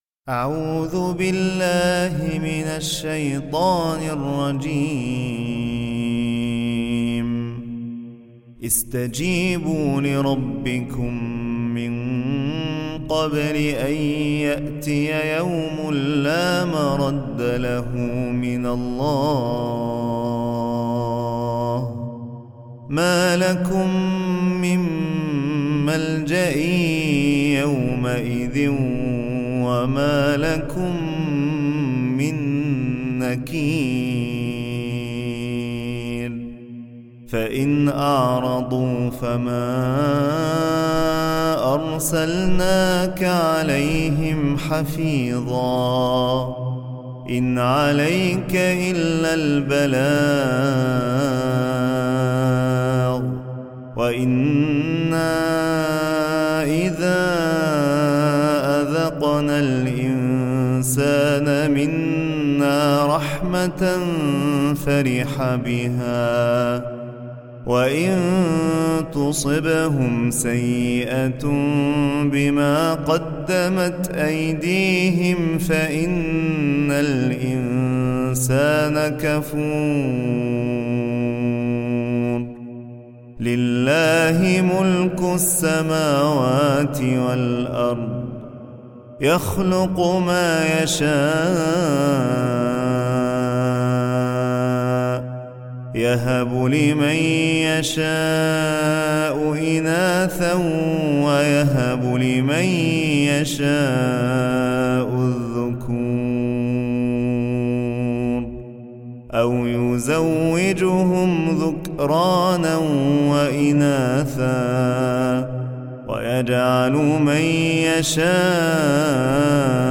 التلاوات القرآنية || تلاوة لخواتيم سورة { الشورى }
تلاوة هادئة (خواتيم سورة الشورى)